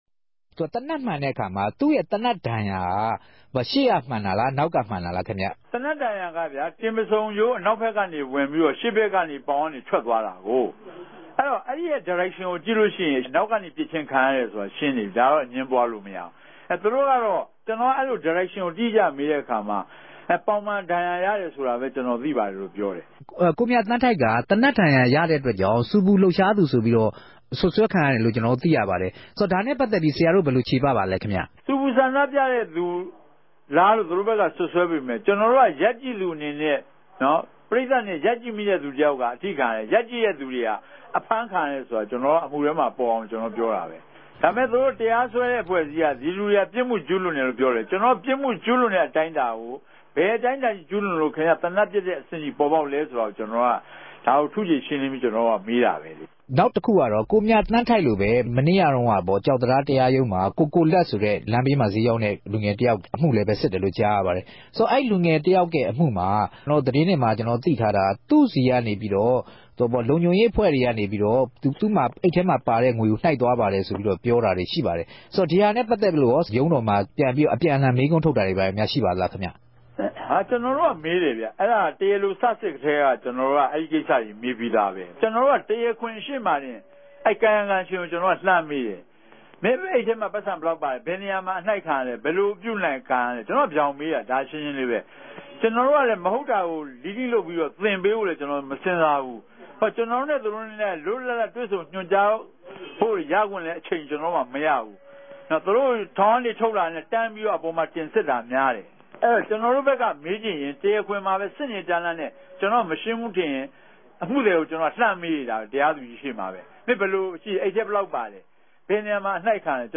မေးူမန်းထားတာကို နားဆငိံိုင်ပၝတယ်။